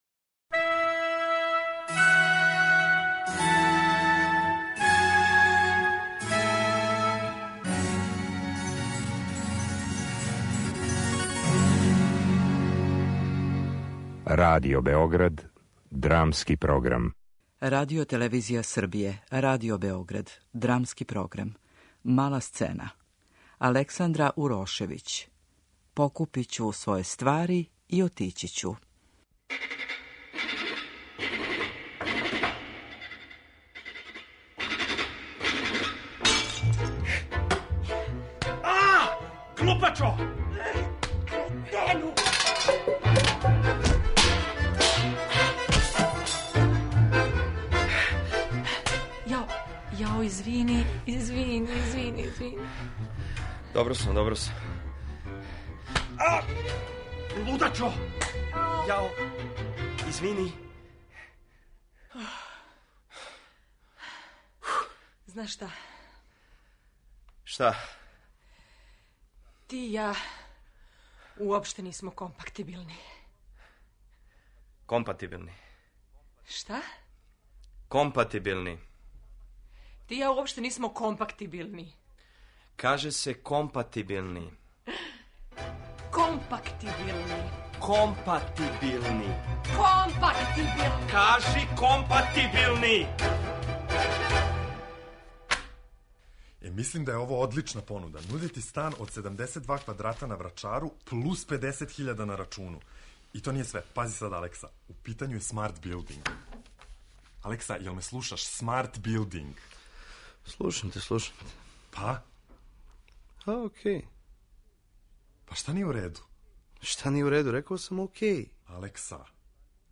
Драмски програм: Мала сцена